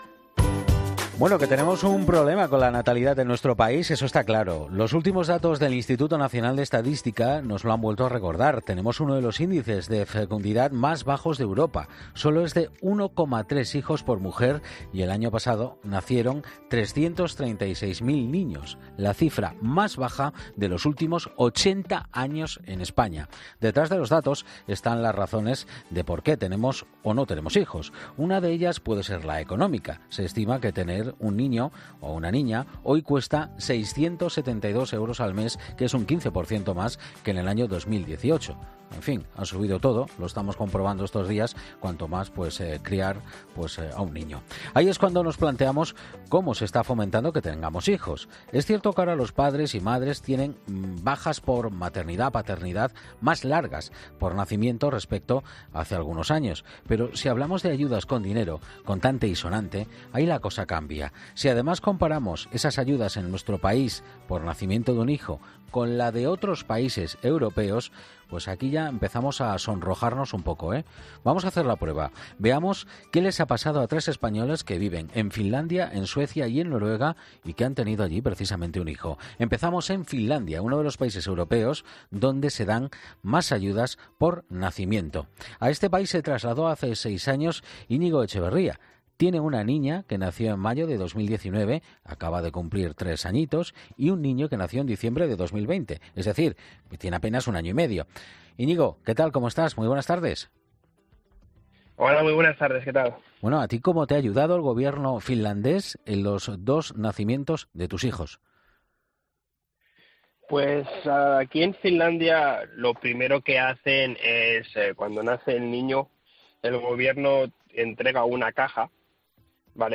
Hablamos con tres padres españoles en 'Mediodía COPE' que nos explican todas las prestaciones que reciben y la manera en la que concilian vida...